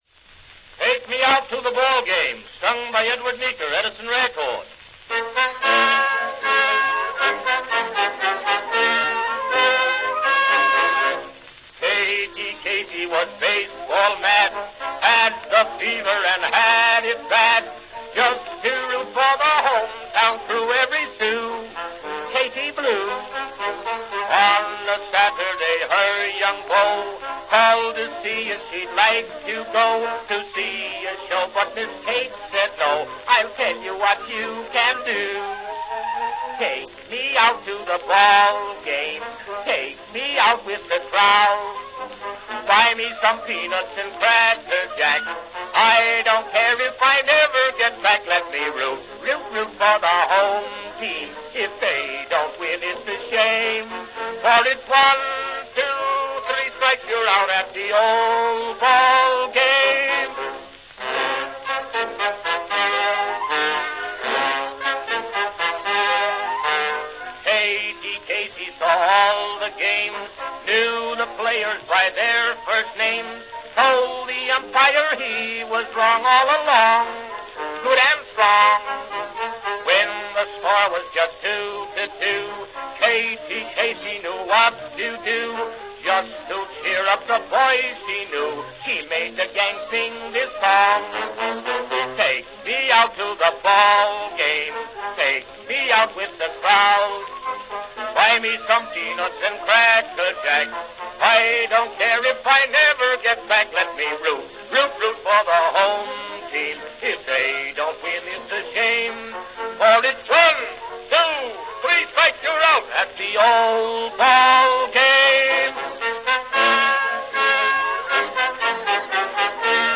RealAudio file from a wax cylinder recording at Early Recorded Sounds and Wax Cylinders.